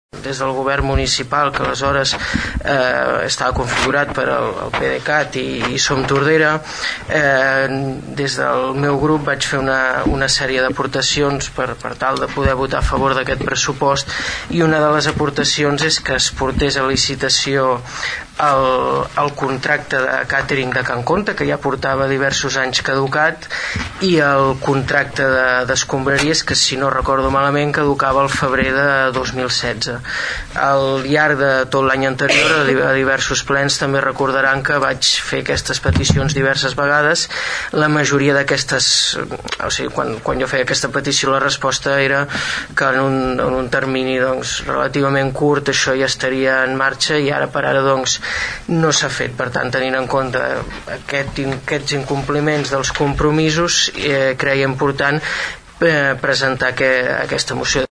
El regidor del PP, Xavier Martín, va demanar al govern municipal que si s’abstenia o votava a favor de la moció, s’ho creguessin i duguessin a terme les mesures.